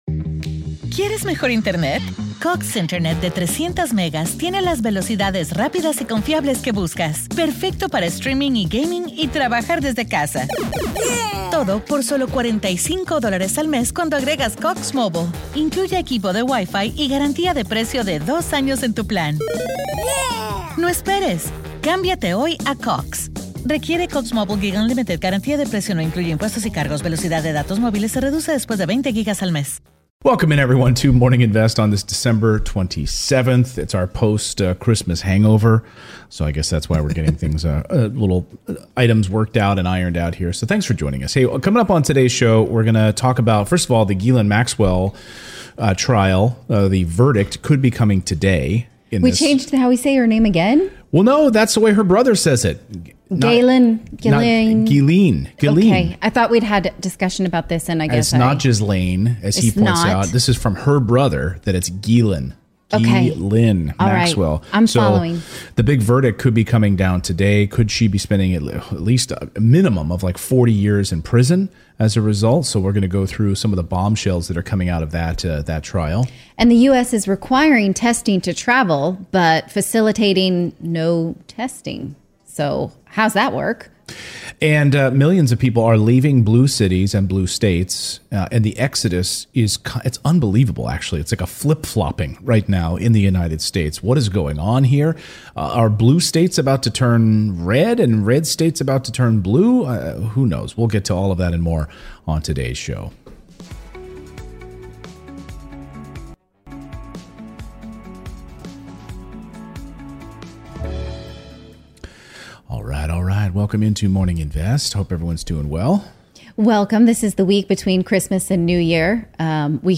In today's livestream... the jury returns to deliver a verdict in the Ghislaine Maxwell trial. America's travel nightmare as testing is to blame. And is President Biden about to step down?